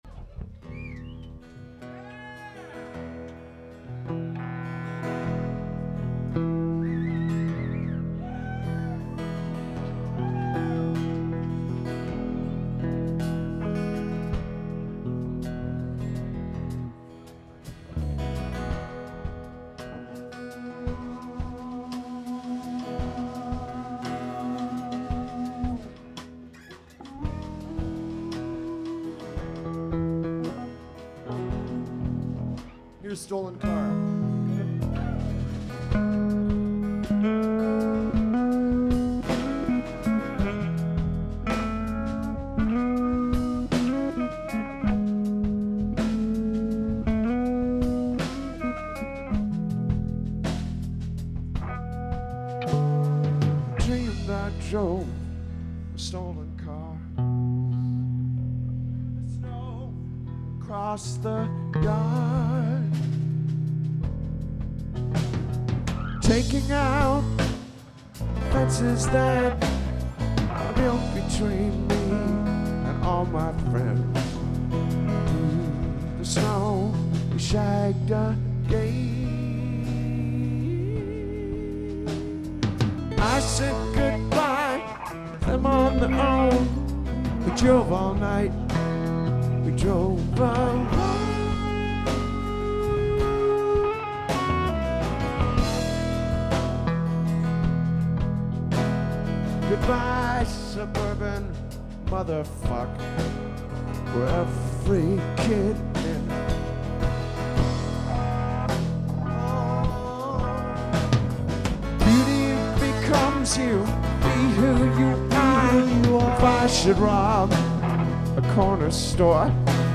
The Casbah Hamilton Ontario November 6, 2004
Recording: Soundboard > Tascam DA-P1